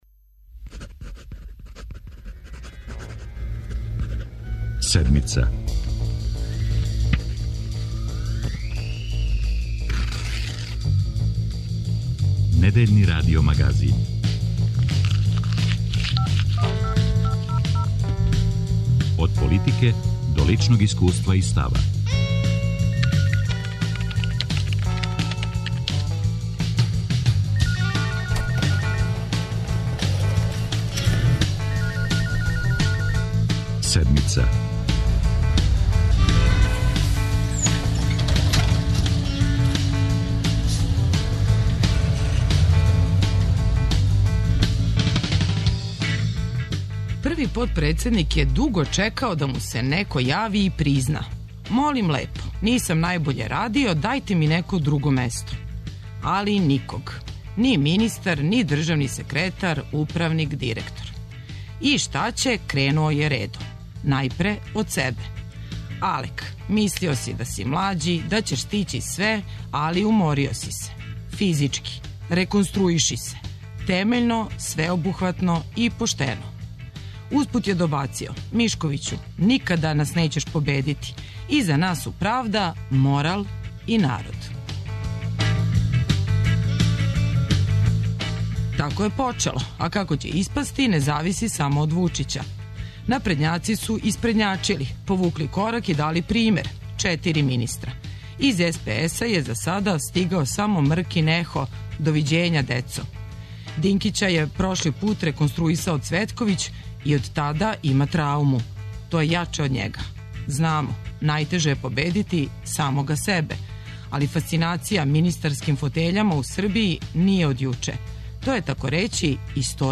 О реконструкцији Владе, коалиционом договору, односу према фотељи и проблемима у здравственом систему - за Седмицу говори потпредседница главног одбора СПС-а и министарка здравља Славица Ђукић Дејановић.